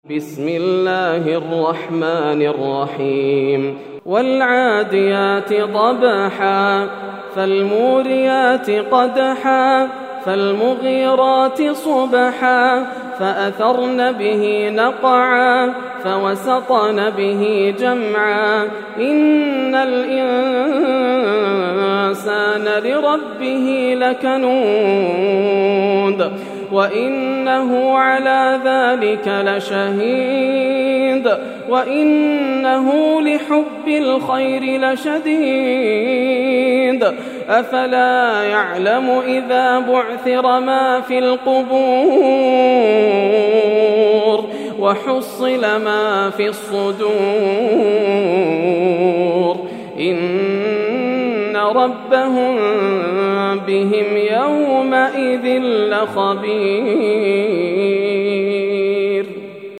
سورة العاديات > السور المكتملة > رمضان 1431هـ > التراويح - تلاوات ياسر الدوسري